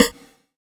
SNARE 40  -R.wav